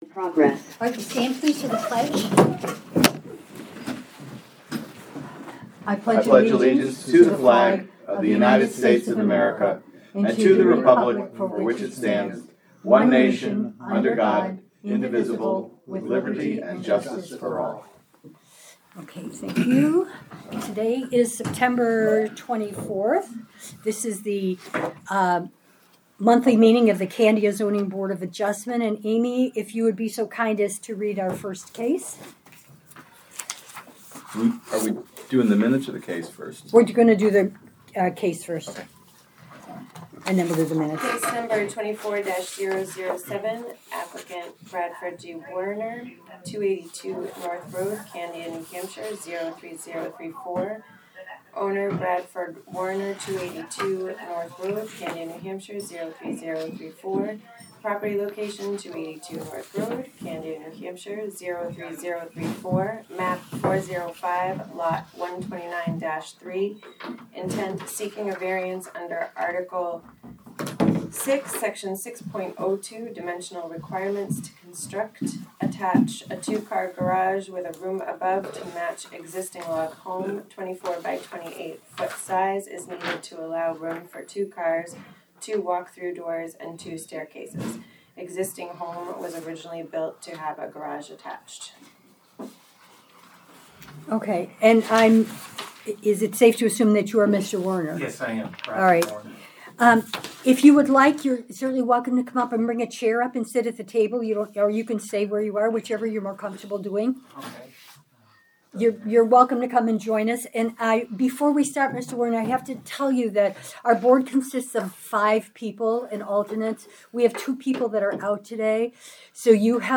Audio recordings of committee and board meetings.
Zoning Board of Adjustment Meeting